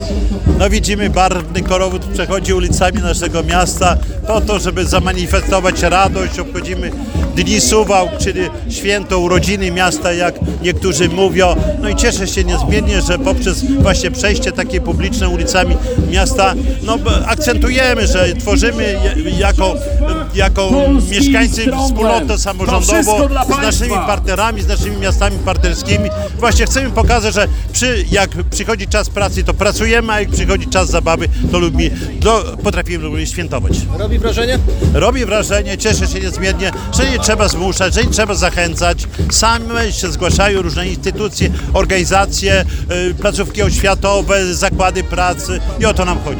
Barwna parada z udziałem setek osób rozpoczęła w sobotę (09.08) Dni Suwałk, czyli coroczne święto miasta.